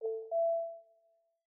Knock Notification 11.wav